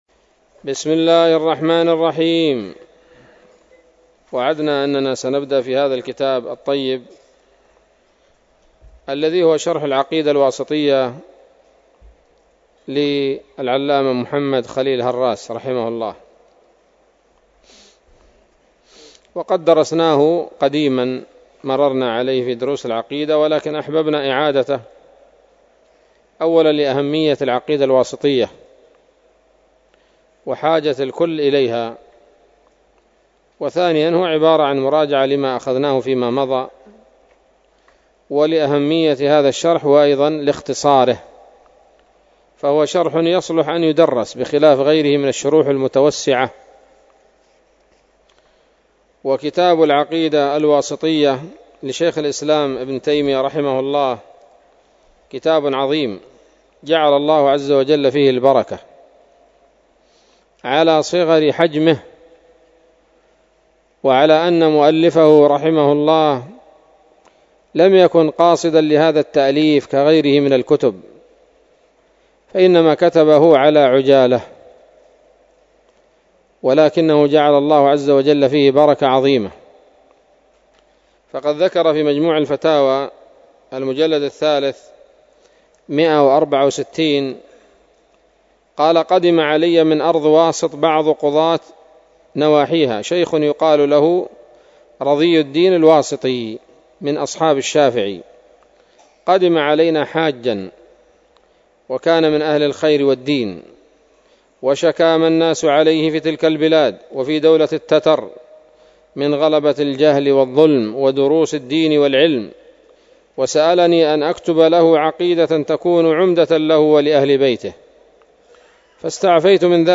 الدرس الأول من شرح العقيدة الواسطية للهراس